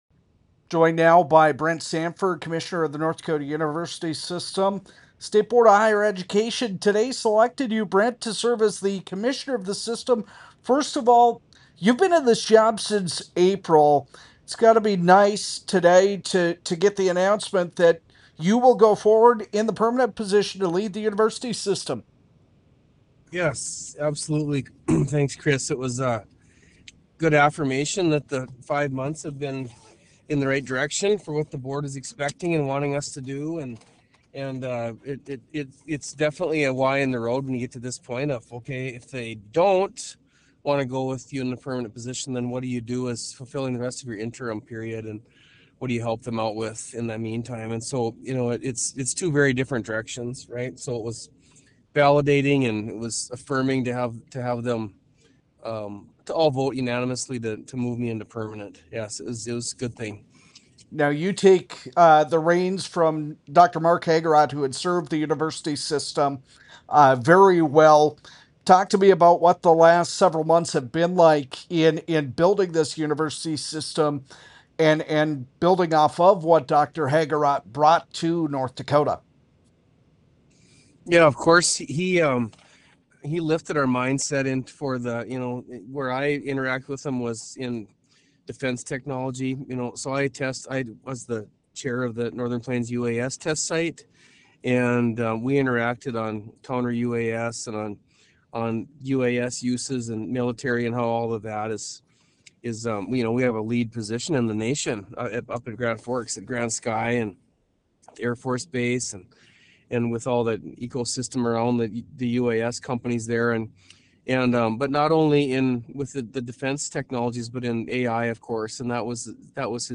North Dakota University System Commissioner Brent Sanford’s interview